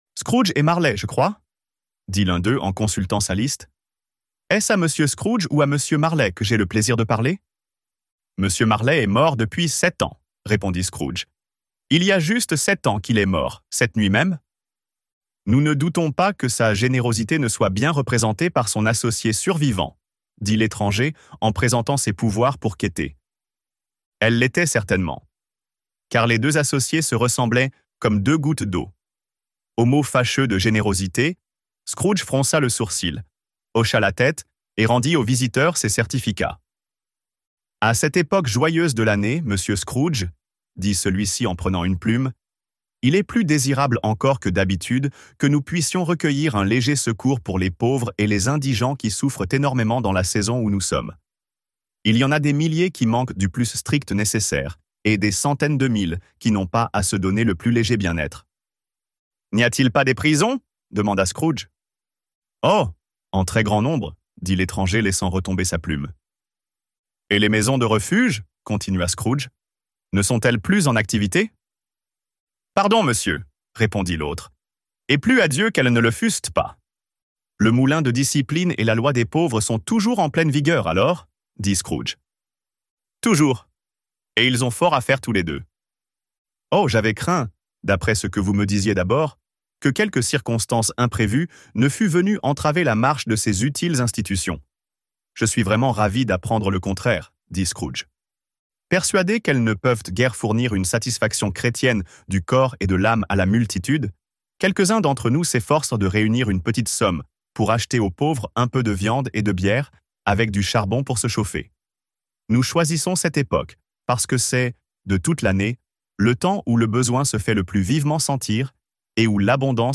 Un chant de Noël - Livre Audio